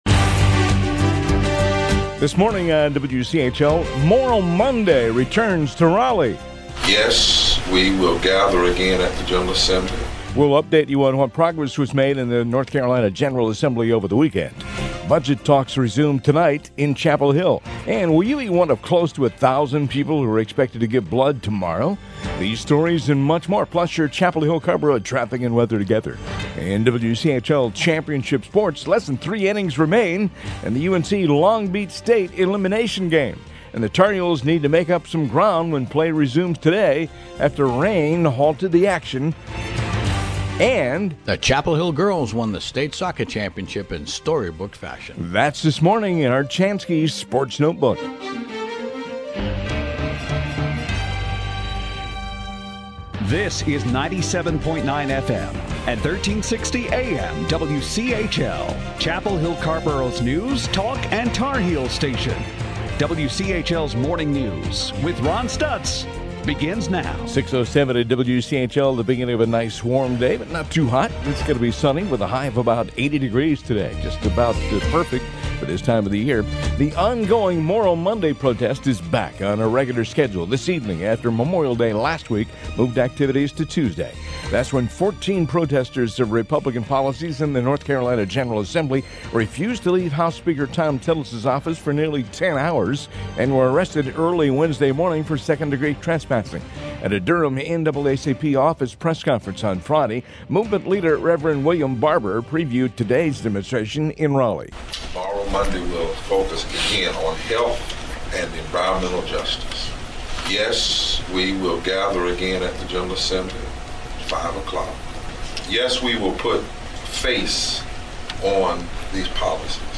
WCHL MORNING NEWS HOUR 1.mp3